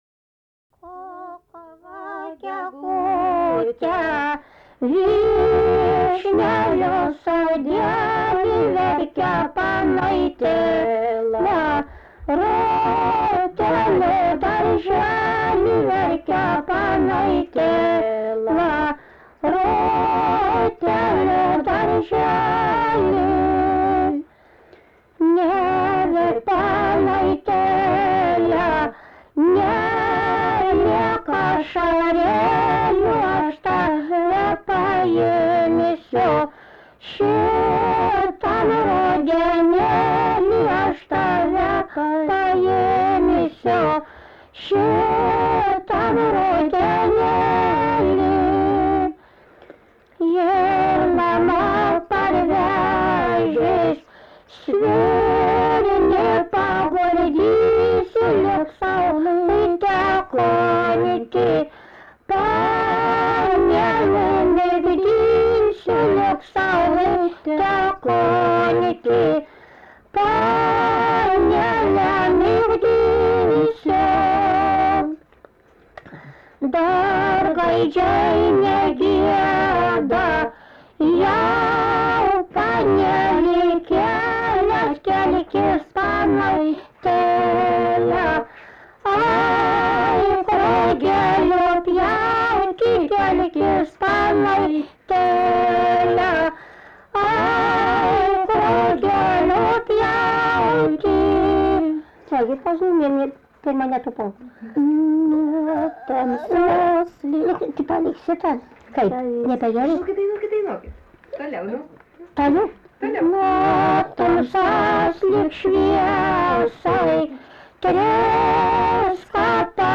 daina
Rundžiai
vokalinis